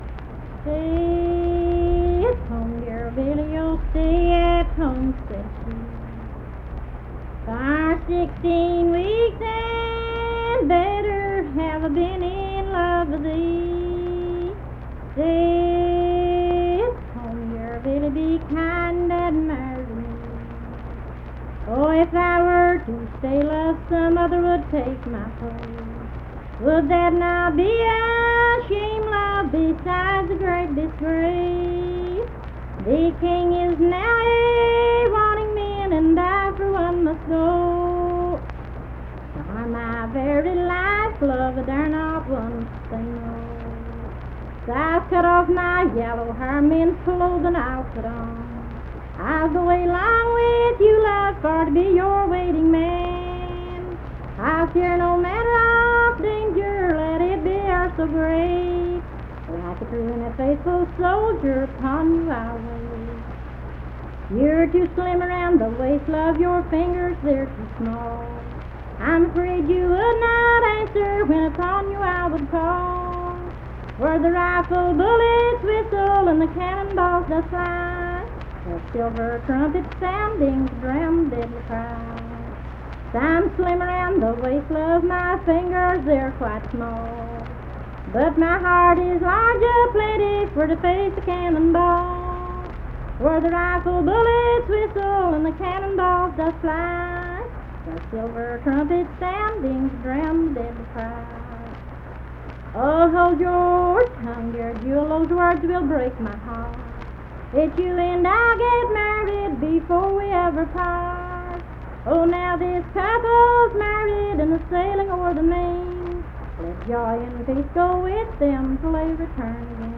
Unaccompanied vocal music
Verse-refrain 7(8).
Voice (sung)
Lincoln County (W. Va.), Harts (W. Va.)